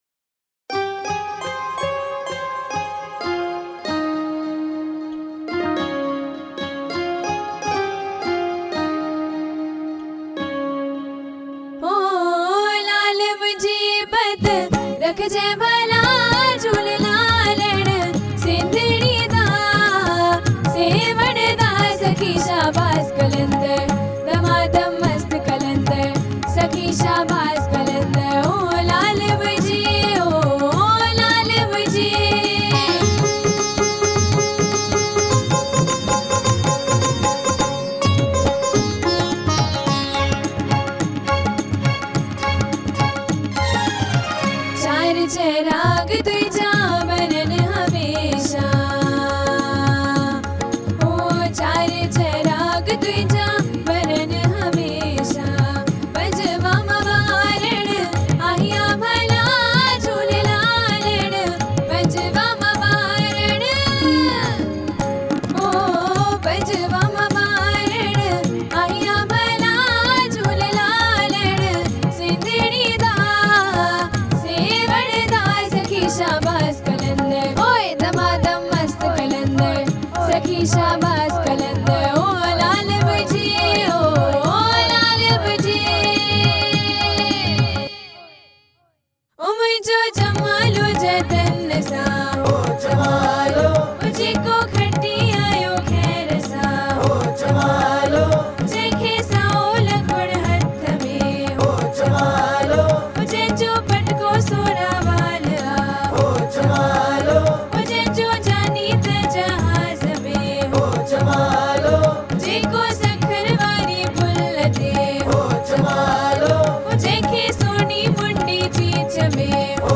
Male Voice